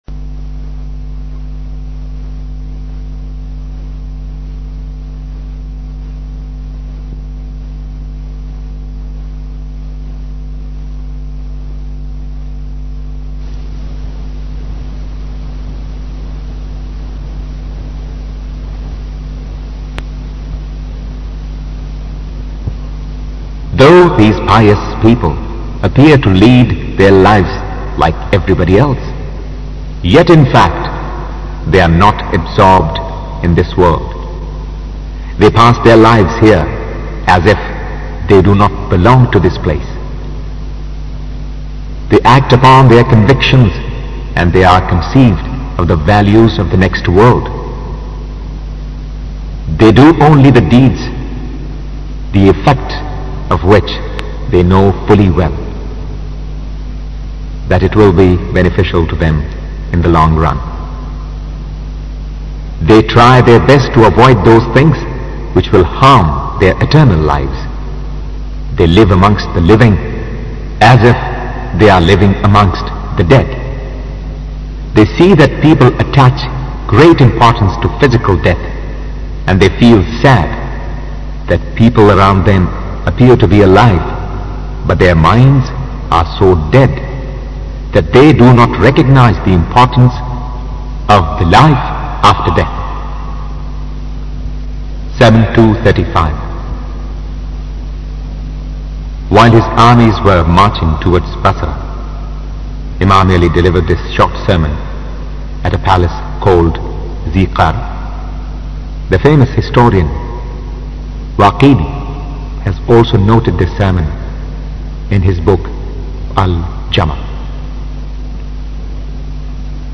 sermons_227_to_247_b